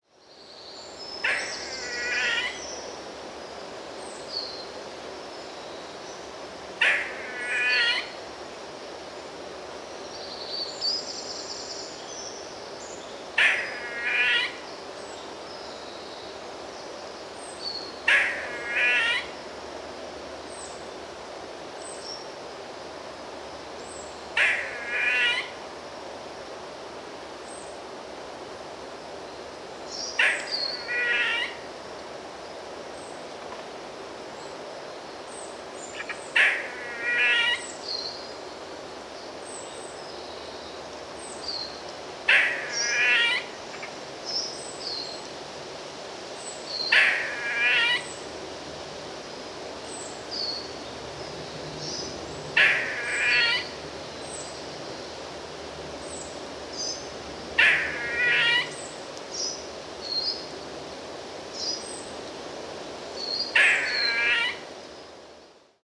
Audio Call